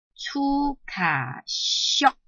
拼音查詢：【南四縣腔】xiog ~請點選不同聲調拼音聽聽看!(例字漢字部分屬參考性質)